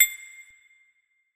Lucki Perc (2).wav